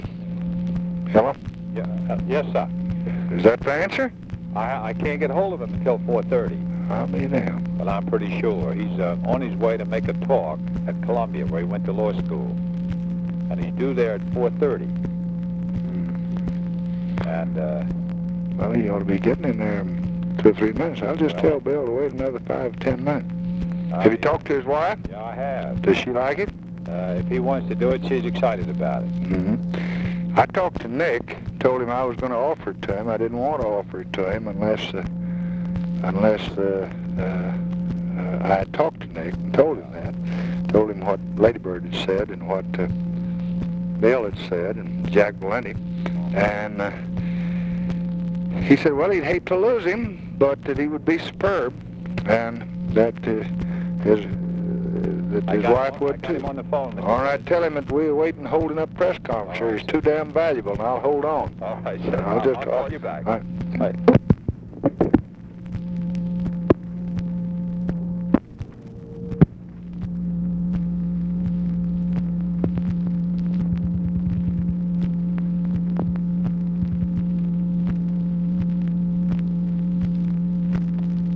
Conversation with STUART SYMINGTON, March 18, 1966
Secret White House Tapes